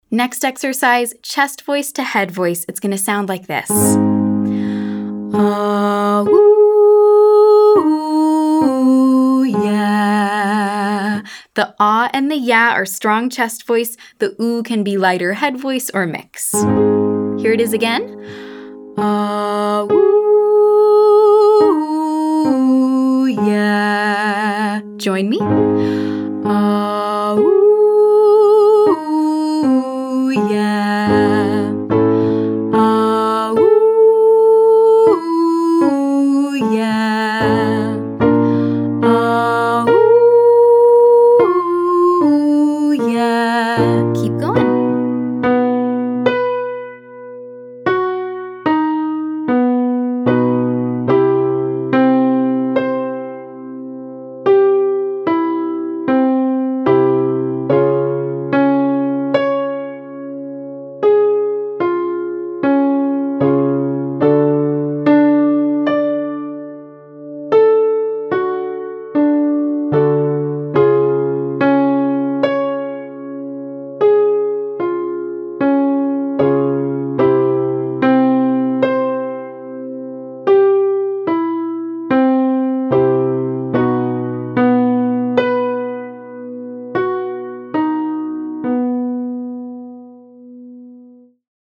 Quick warmup
Exercise 4: Chest to head AH-oo-yeah 18-531